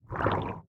Minecraft Version Minecraft Version snapshot Latest Release | Latest Snapshot snapshot / assets / minecraft / sounds / mob / squid / ambient2.ogg Compare With Compare With Latest Release | Latest Snapshot